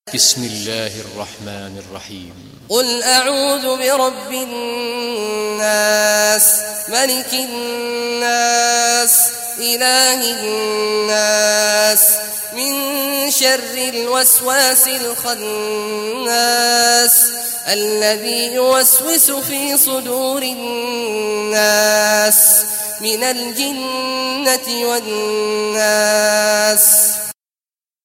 Surah Nas Recitation by Sheikh Awad Juhany
Surah Nas, listen or play online mp3 tilawat / recitation in Arabic in the beautiful voice of Sheikh Abdullah Awad al Juhany.